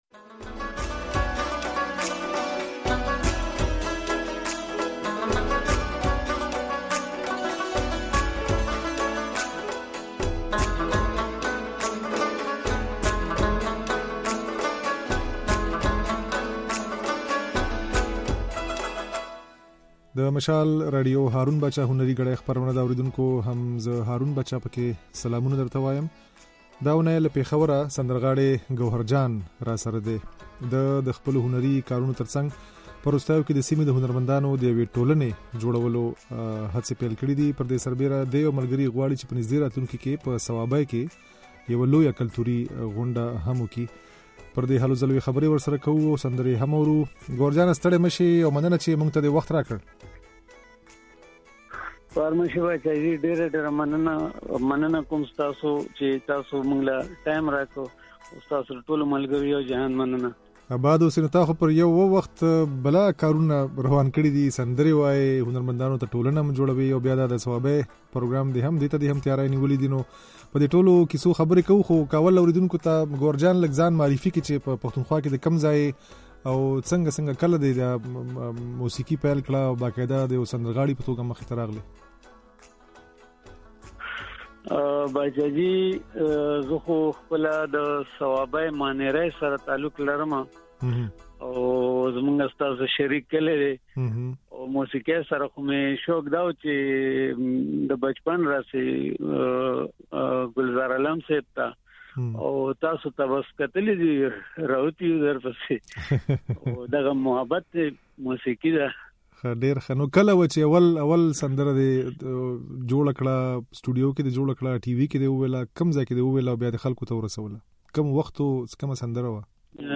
خبرې او د هغه څو سندرې د غږ په ځای کې اورېدای شئ